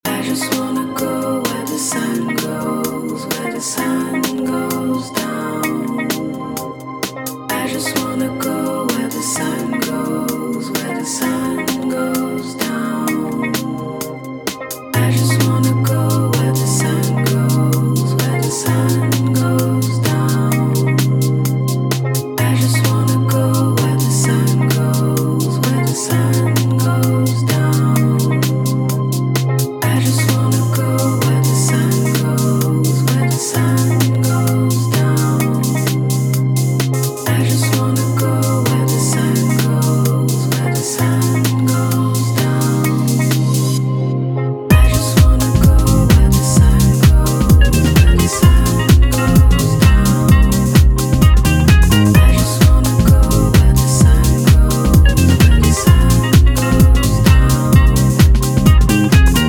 garage and tribal house